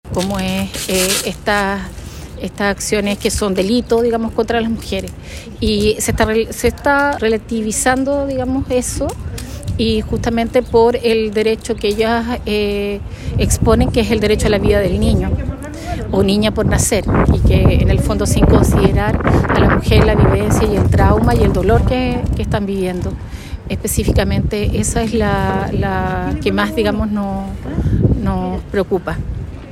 actualidad Entrevista Local